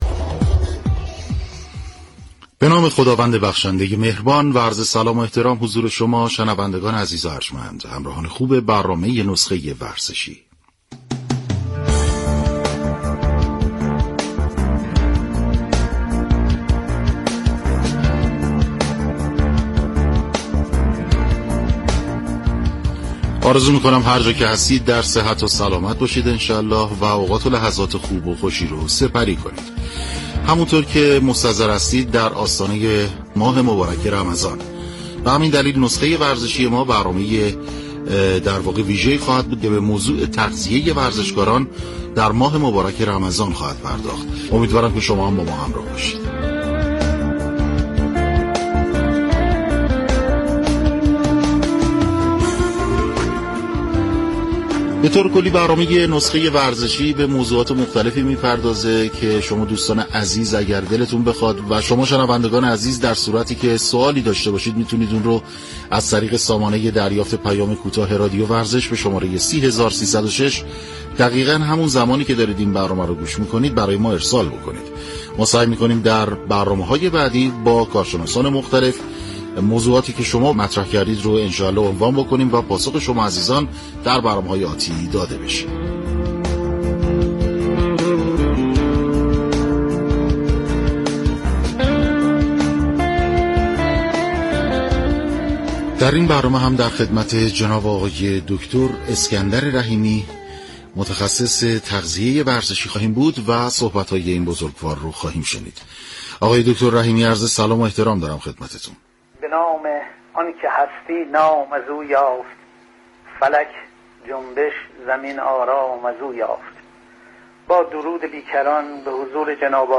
در گفت وگو با برنامه نسخه ورزشی رادیو ورزش